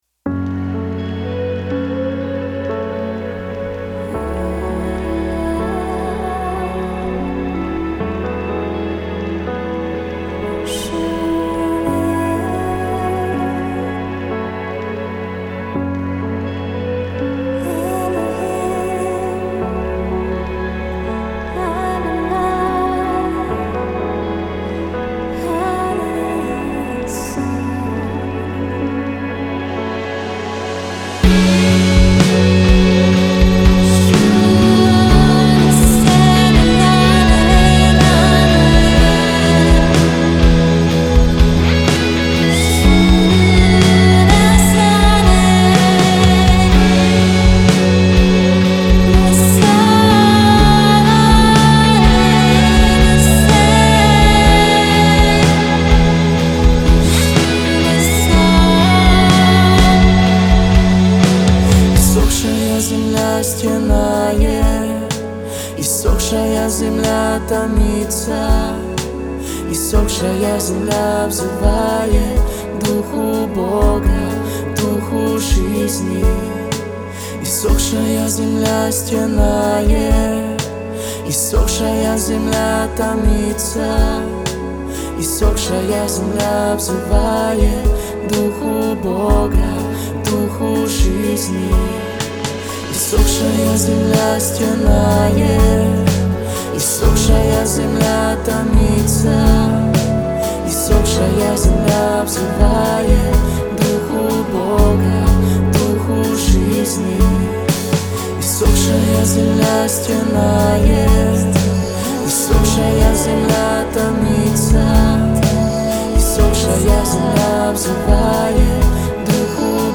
песня
48 просмотров 124 прослушивания 11 скачиваний BPM: 62